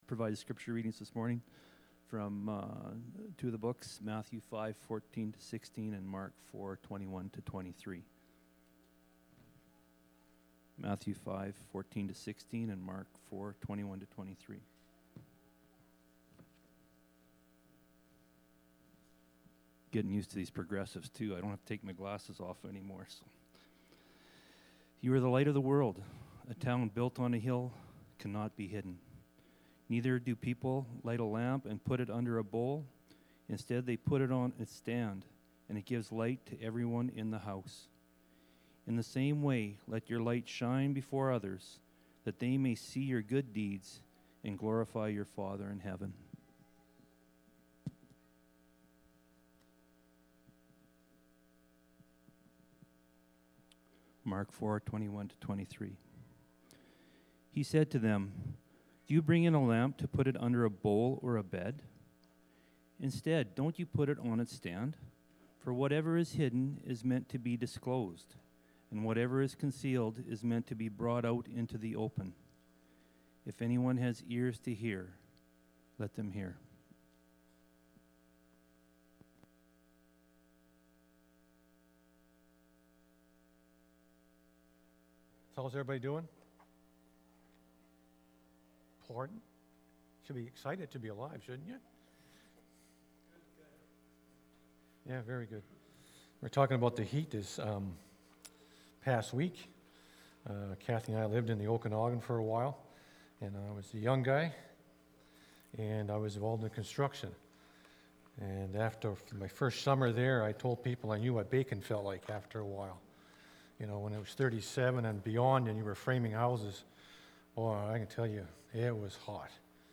July-4-2021-sermon-audio.mp3